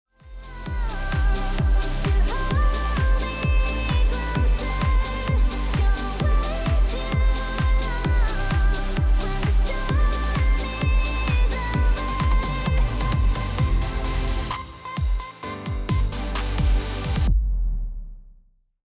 Super Sawは現代のEDMやダンスミュージックに欠かせない定番サウンドです。
複数のSaw波形（ノコギリ波）を重ね合わせることで、豊かな倍音と厚みのある質感を生み出します。
▼Super Saw サウンドサンプル
Super-Saw-Sound.mp3